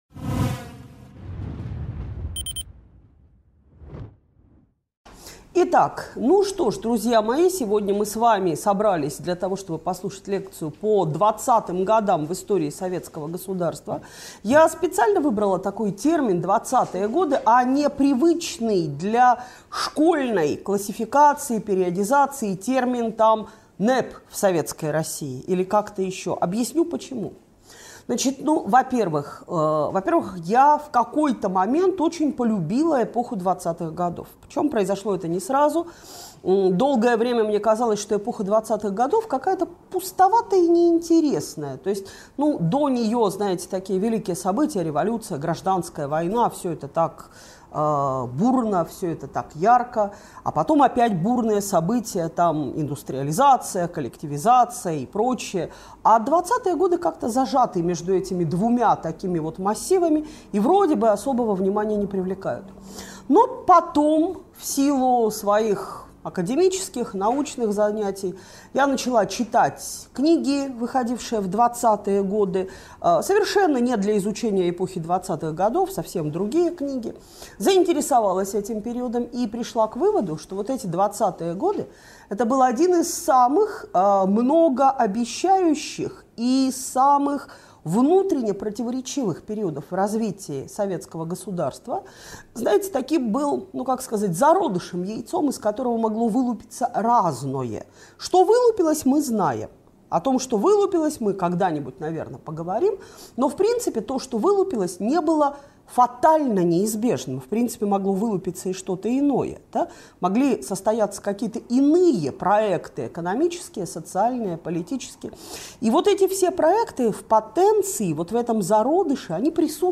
Лекция о начальном этапе развития Советского Государства. Эпоха двадцатых годов двадцатого века стала временем кардинальных перемен во всем мире.